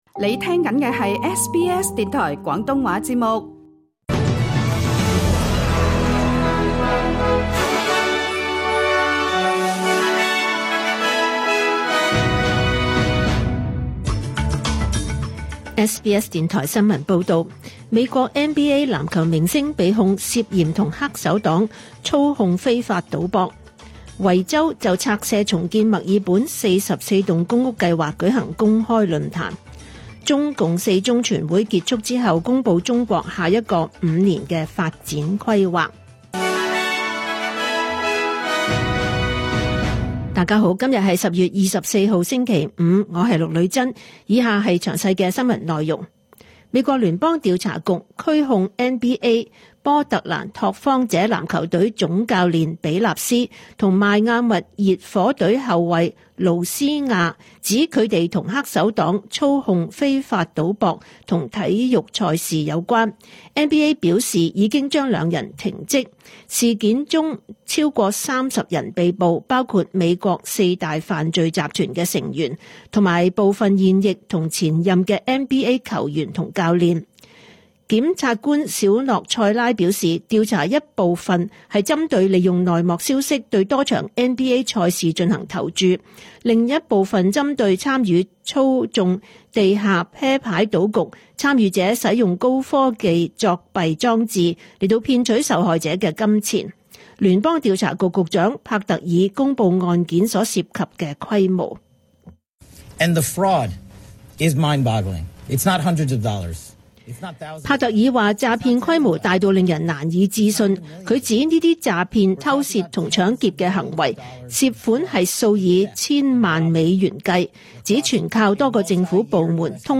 2025 年 10 月 24 日 SBS 廣東話節目詳盡早晨新聞報道。